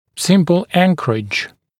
[‘sɪmpl ‘æŋkərɪʤ][‘симпл ‘энкэридж]простая анкеровка